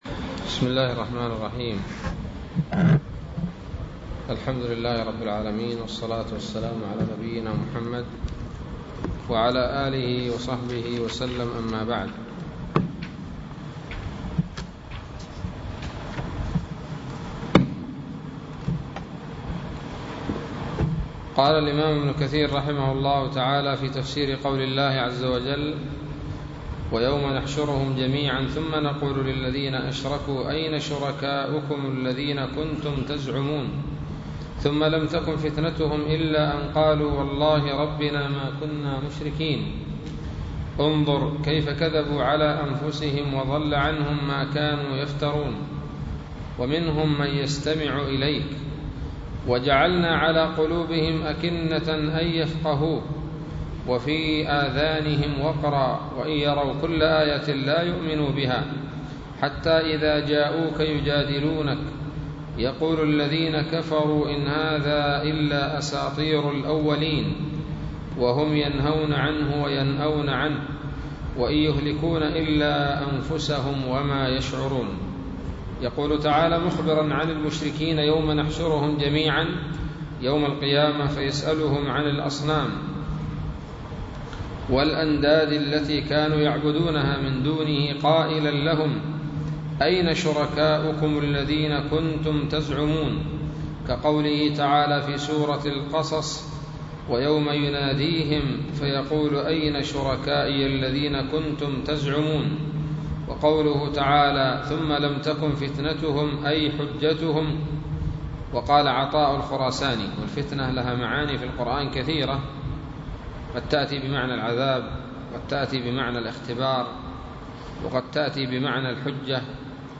006 سورة الأنعام الدروس العلمية تفسير ابن كثير دروس التفسير